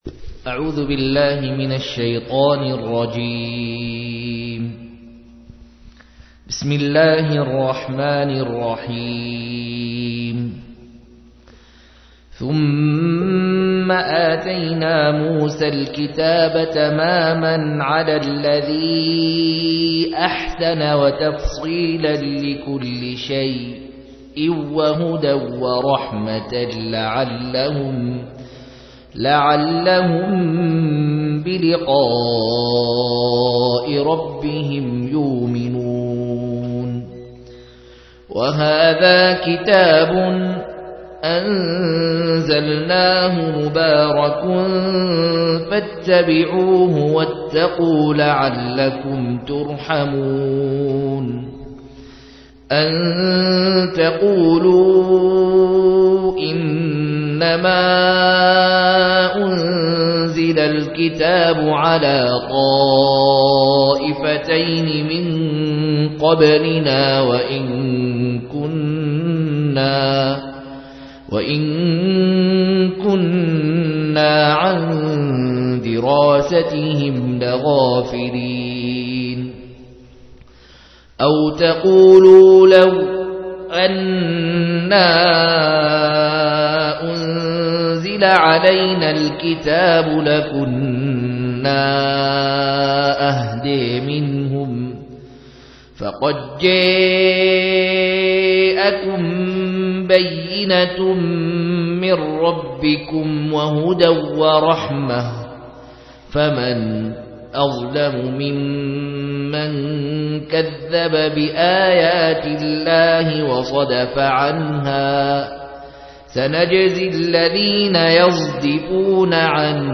142- عمدة التفسير عن الحافظ ابن كثير رحمه الله للعلامة أحمد شاكر رحمه الله – قراءة وتعليق –